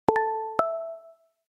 Звуки телефона Xiaomi
• Качество: высокое